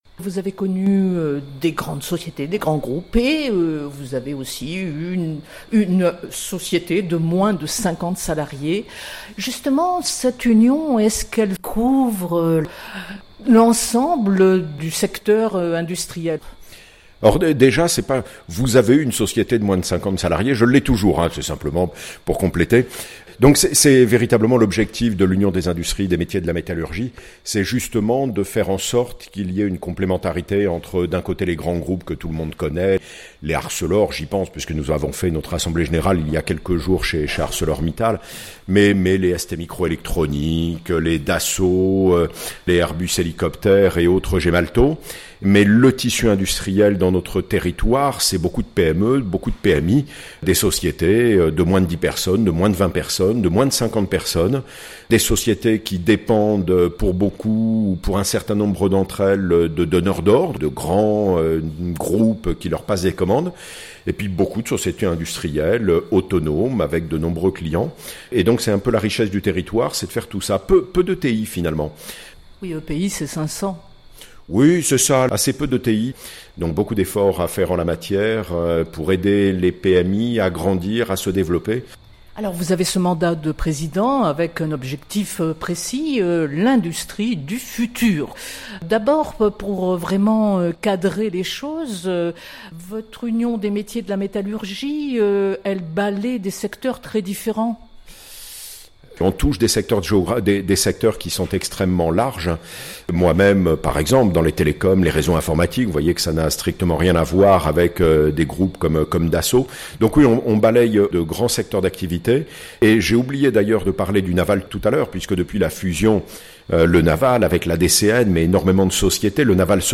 L'industrie, fabrique de l'avenir ? Entretien